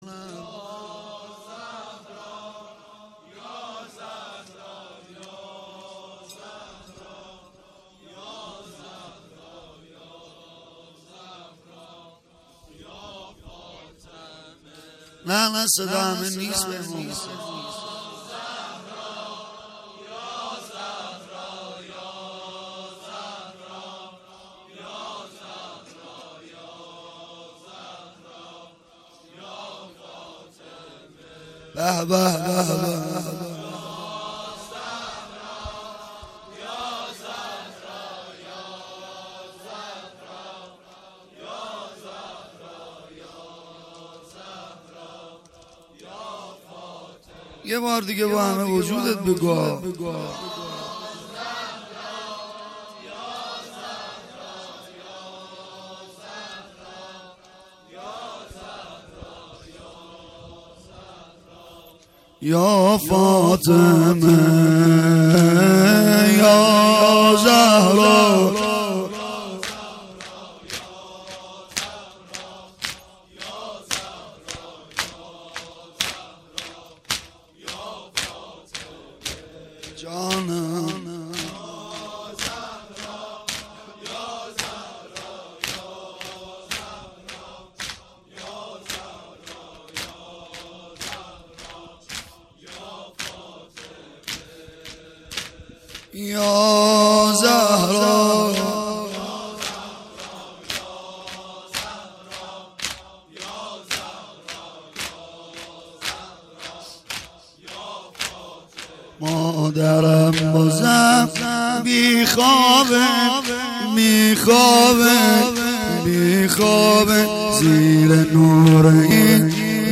قسمت اول -مداحی.mp3
قسمت-اول-مداحی.mp3